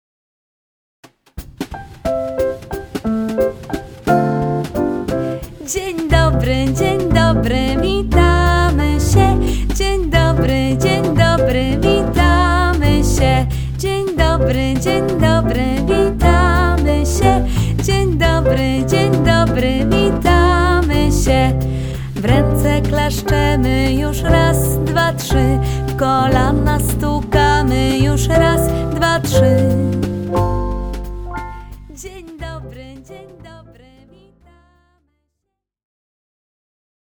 prosta piosenka na powitanie